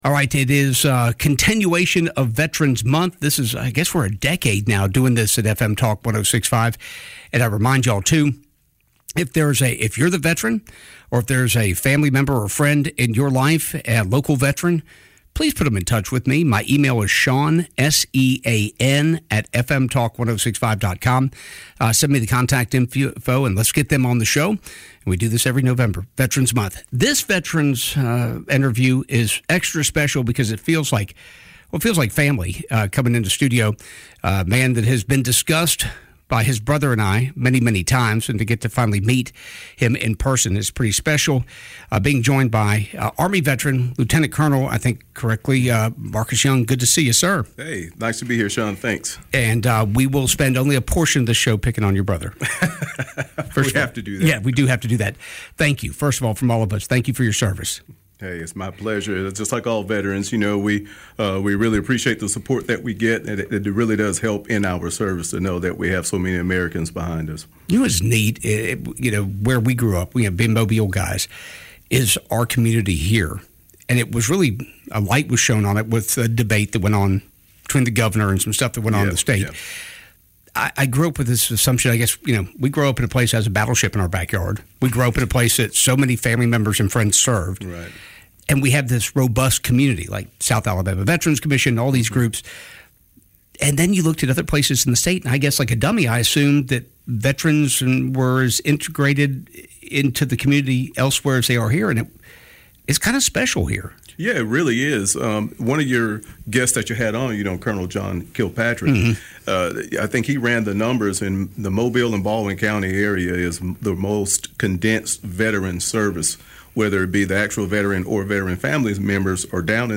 🎙 Listen now to this Veterans Month conversation — part laughter, part reflection, and 100% gratitude.